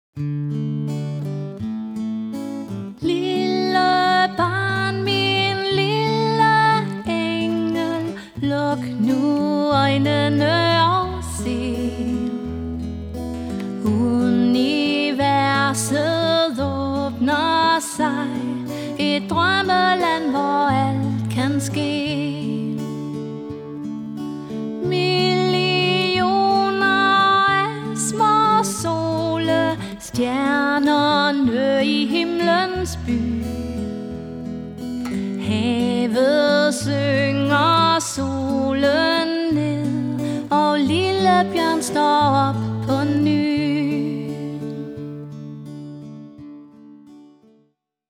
Sangerinde og solomusiker (sang og guitar)
Smukt, akustisk og rørende.
Jeg spiller guitar til og medbringer selv udstyr.